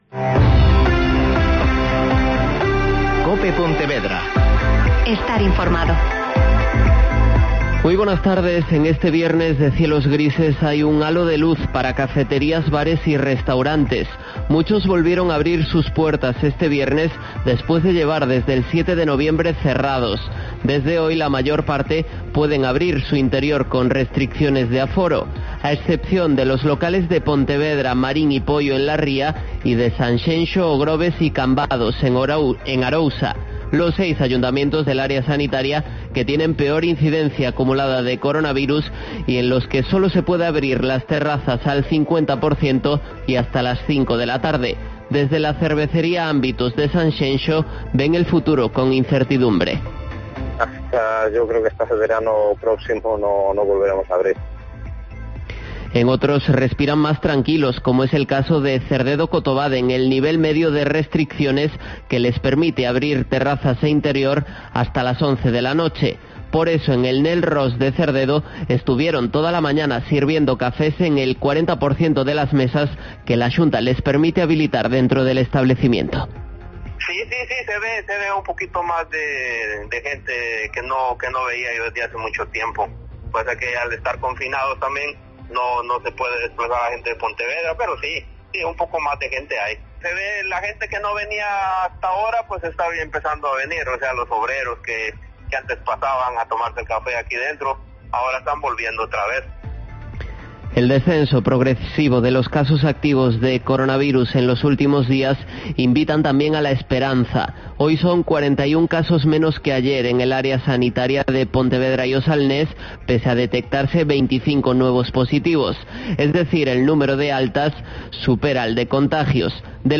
Mediodía COPE Pontevedra (Informativo 14:20)